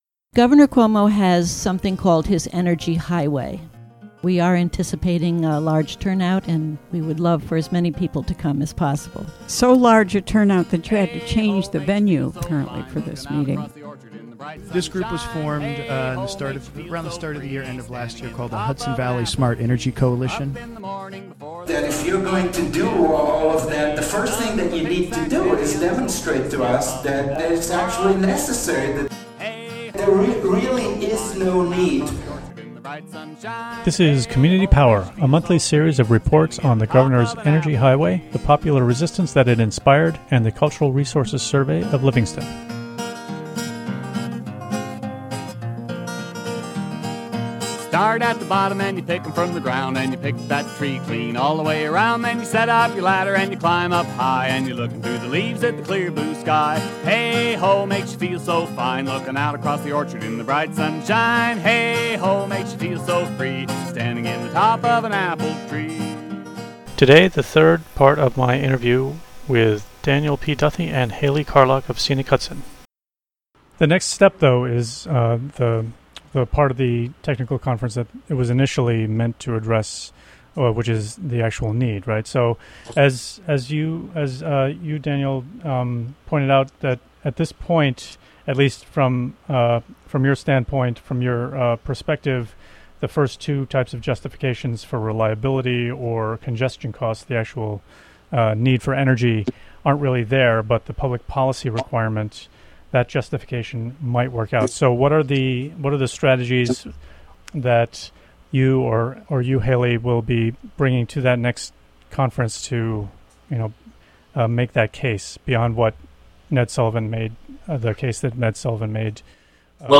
Recorded in the WGXC Hudson Studio, Wed., Jul. 29.